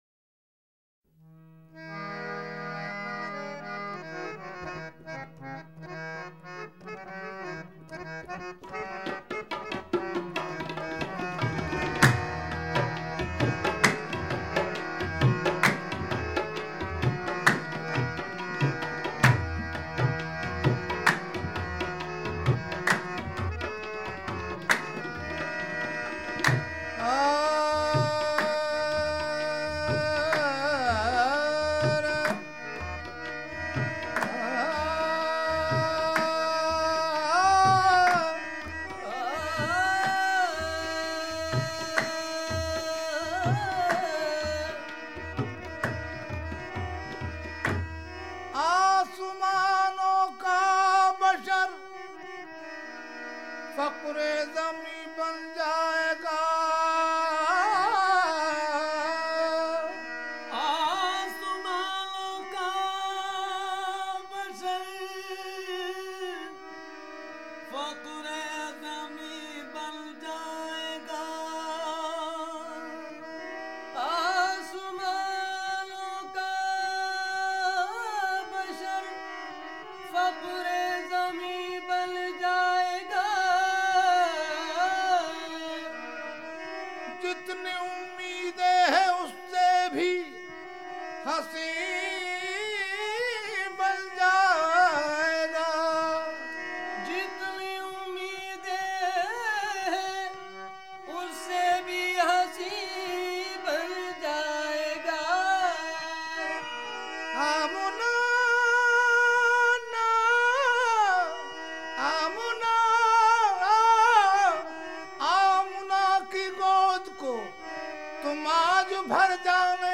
Naat
famed for their soulful voices.